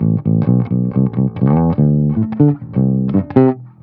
10 Bass Loop A.wav